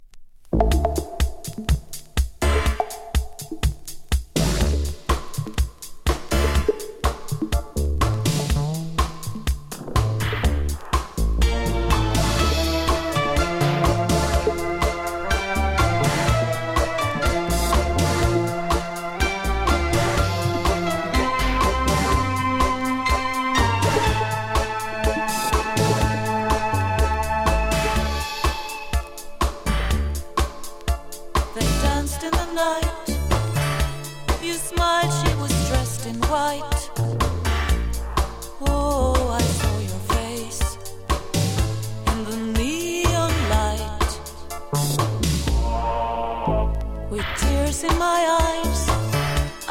レゲエ風味も絶妙。